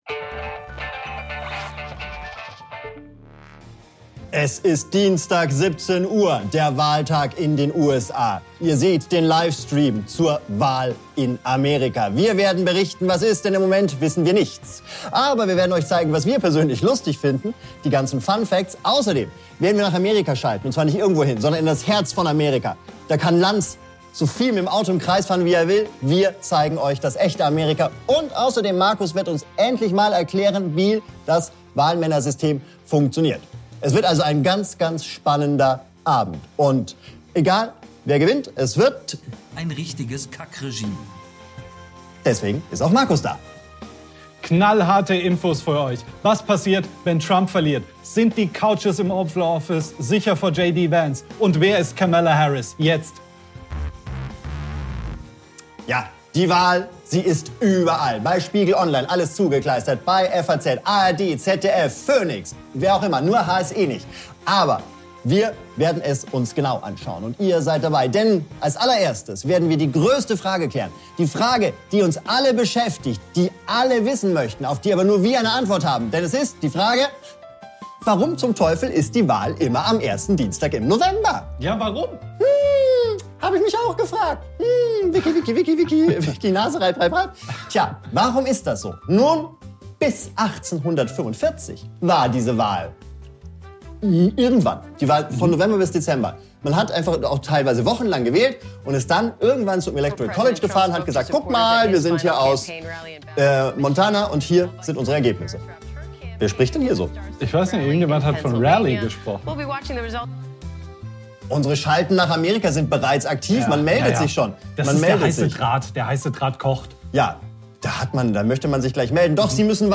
ELECTION DAY! So laufen die Wahlen in den USA ~ WALULIS Live Podcast